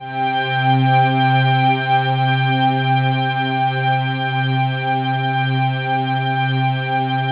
PAD3  C3  -L.wav